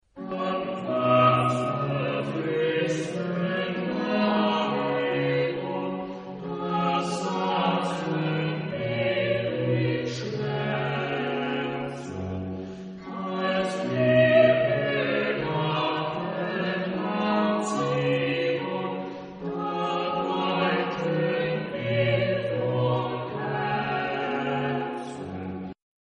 Genre-Style-Forme : Sacré ; Choral ; Romantique
Type de choeur : SATB  (4 voix mixtes )
Tonalité : sol majeur